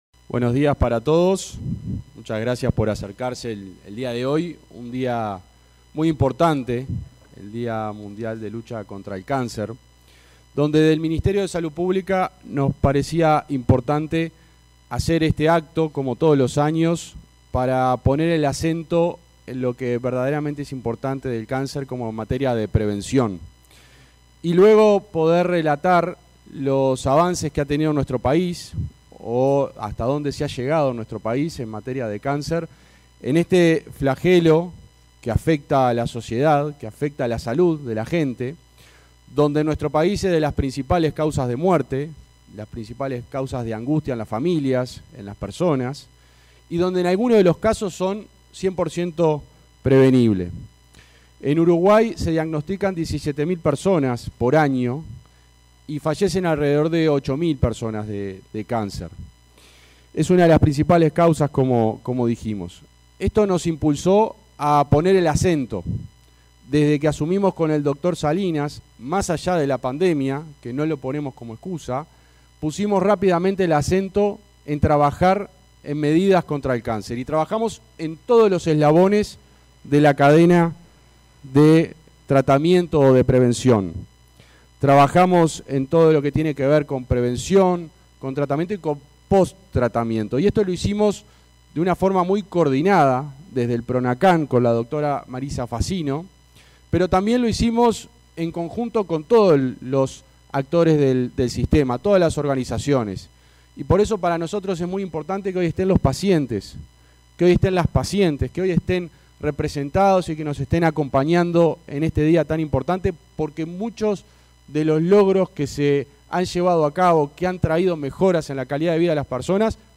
Palabras del ministro interino de Salud Pública, José Luis Satdjian
Palabras del ministro interino de Salud Pública, José Luis Satdjian 04/02/2025 Compartir Facebook X Copiar enlace WhatsApp LinkedIn En la ceremonia conmemorativa del Día Mundial contra el Cáncer, este 4 de febrero, se expresó el ministro interino de Salud Pública, José Luis Satdjian.